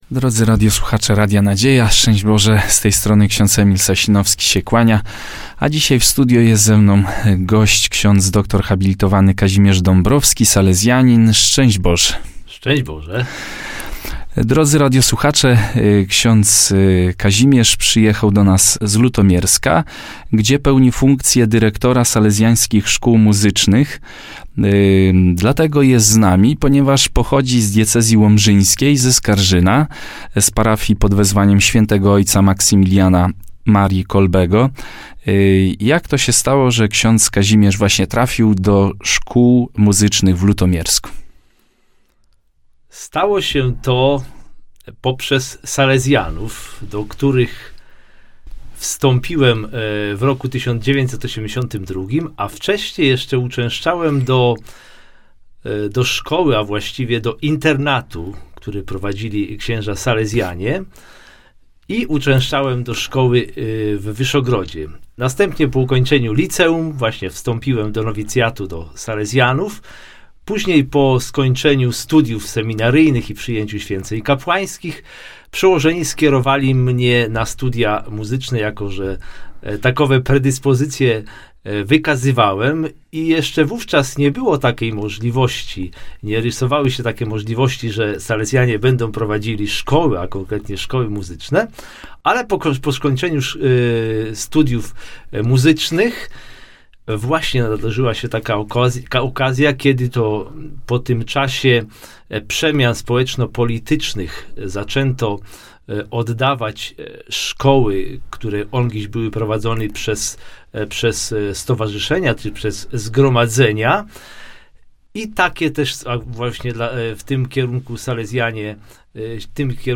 Rozmowa RN: Salezjańskie Szkoły Muzyczne - Radio Nadzieja
Gościliśmy w naszym studio